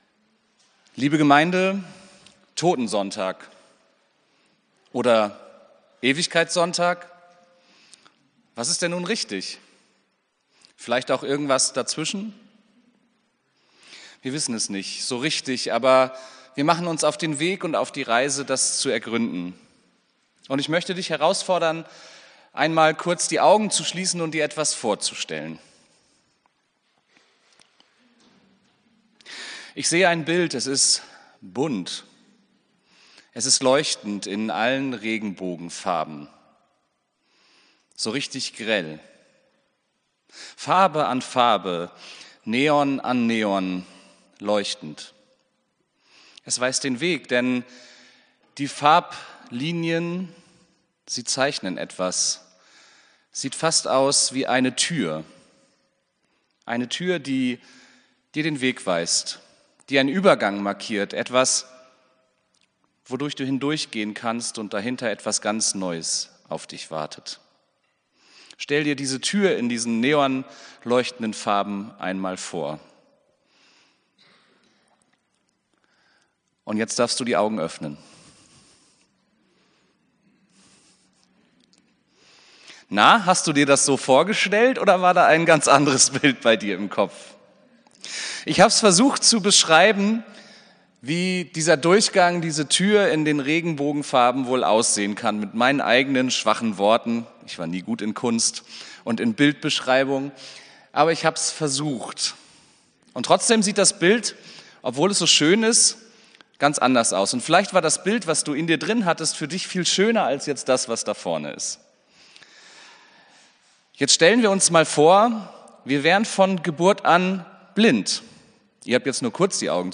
Predigt vom 24.11.2024